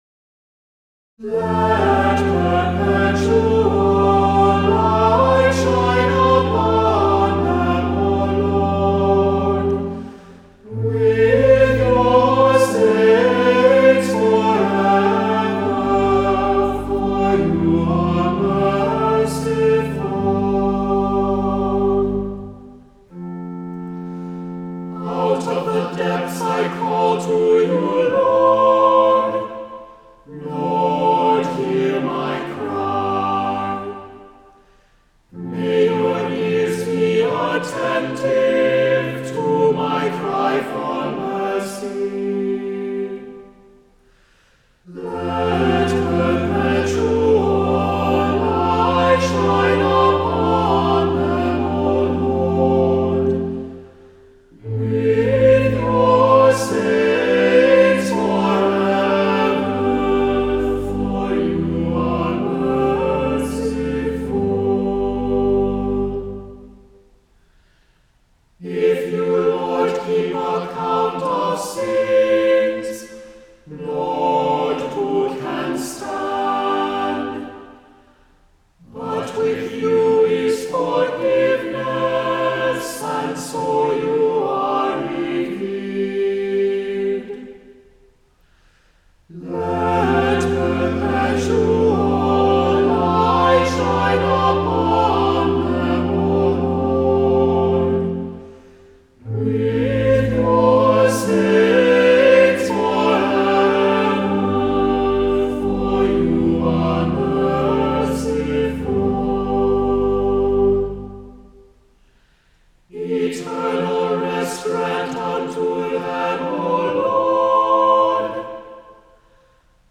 —For Assembly, Schola, SATB Choir, Organ • Roman Missal, 3rd edition.